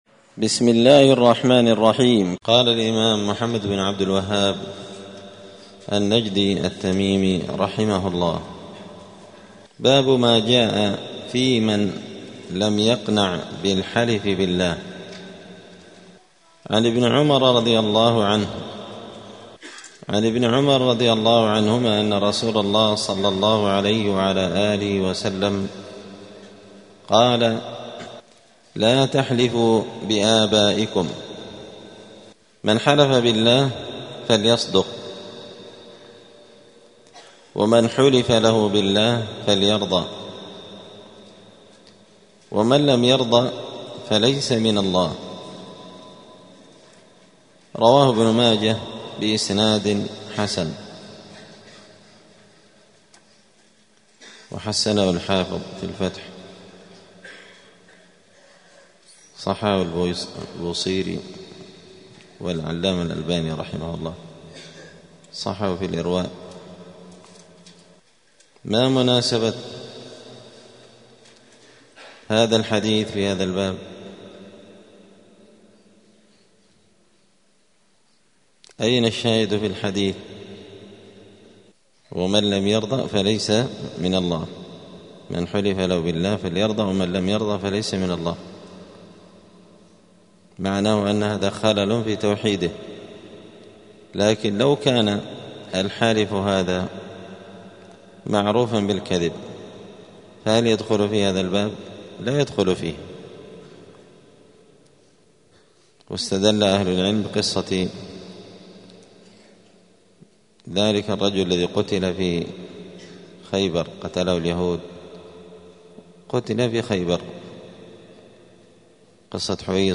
دار الحديث السلفية بمسجد الفرقان قشن المهرة اليمن
*الدرس الواحد والعشرون بعد المائة (121) {باب ما جاء في من لم يقنع بالحلف بالله}*